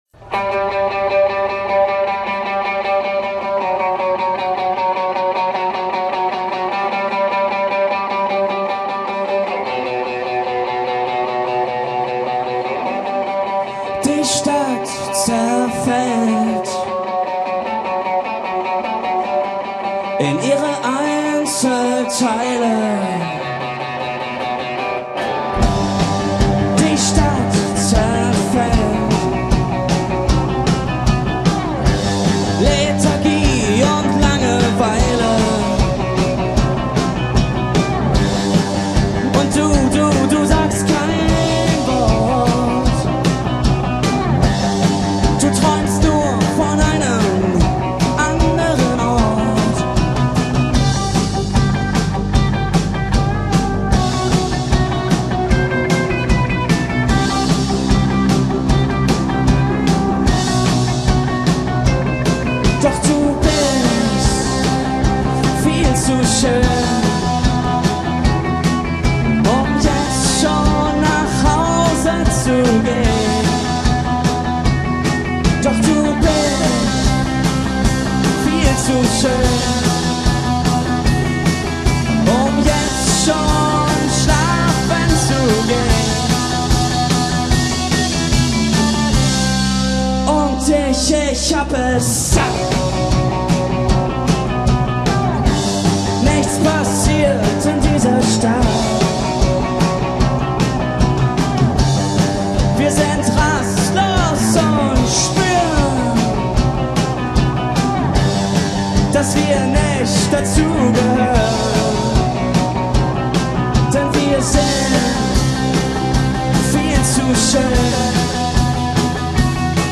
are a three-piece Indie/Alt band from Hamburg Germany